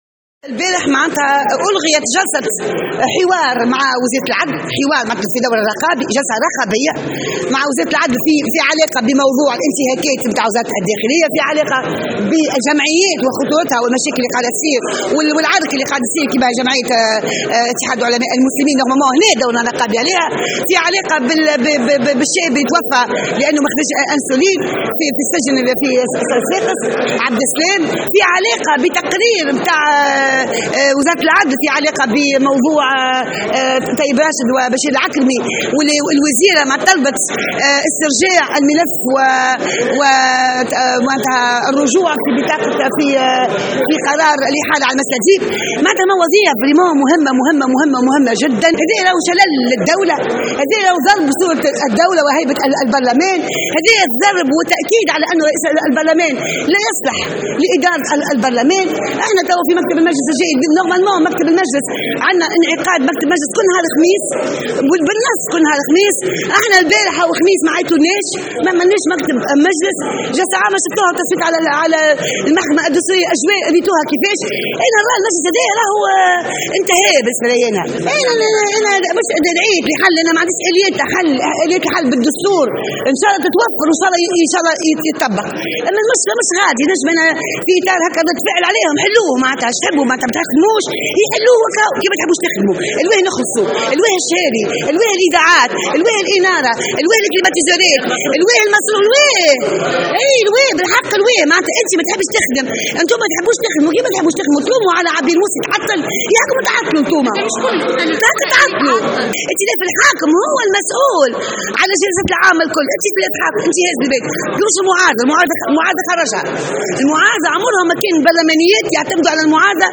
وأكدت عبو في تصريح للجوهرة أف-أم، أن الغياب عن الجلسة متعمد من طرف نواب الحزب الحاكم وأنهم قاموا بانسحاب "تكتيكي" معتبرة أن المجلس قد انتهى.